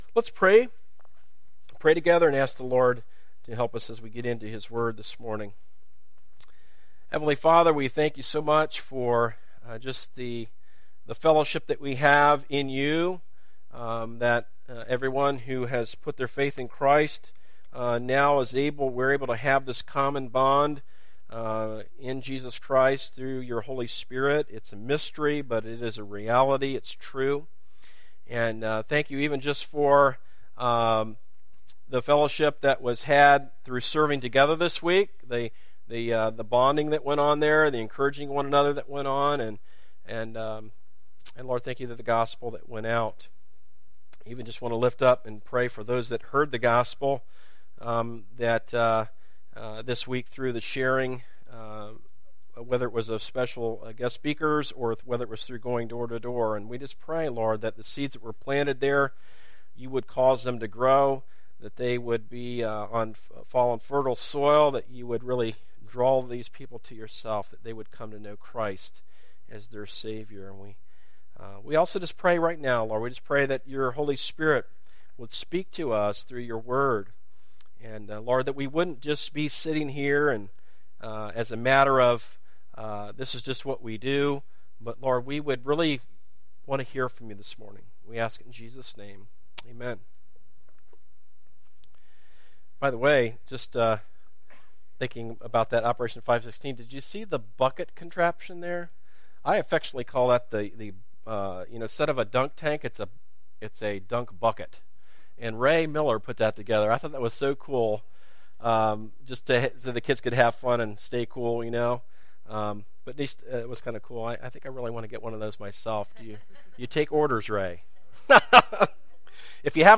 Recent Sermon - Darby Creek Church - Galloway, OH